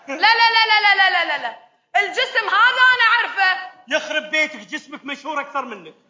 2_khaliji_play.mp3